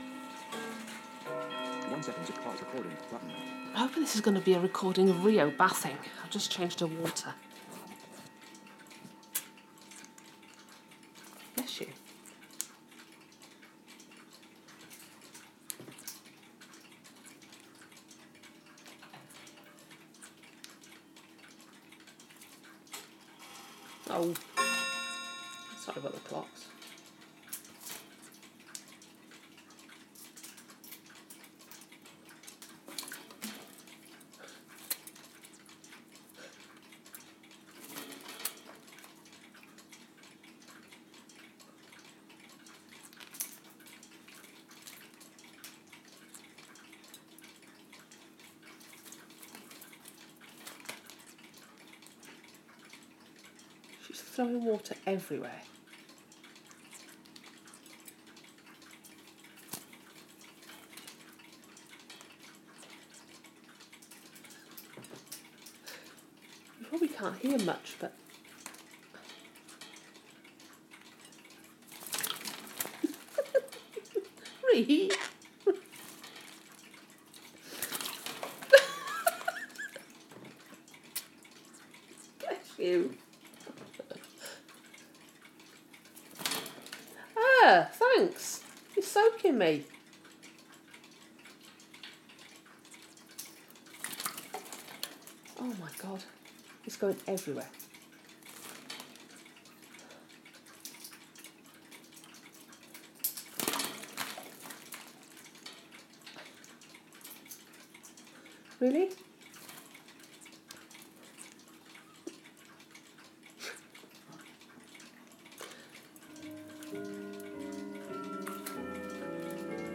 Rio bathing in her water bowl.